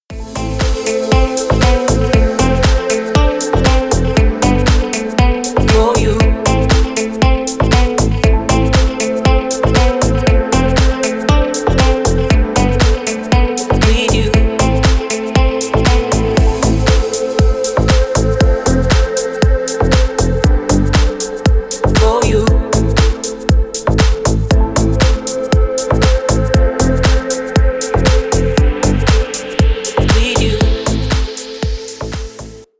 бесплатный рингтон в виде самого яркого фрагмента из песни
Танцевальные